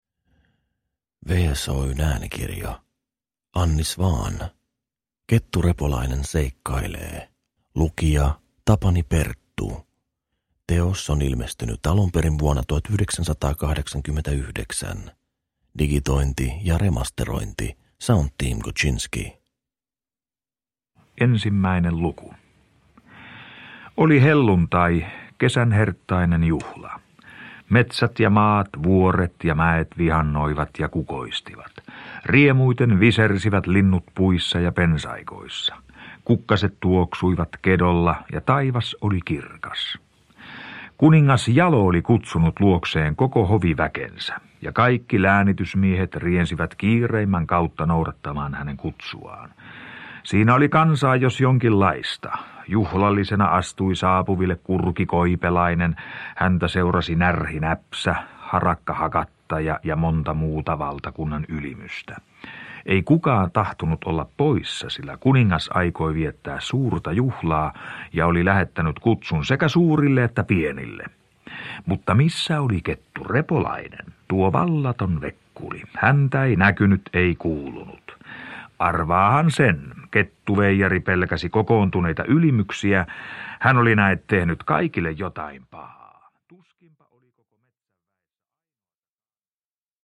Kettu Repolainen seikkailee – Ljudbok – Laddas ner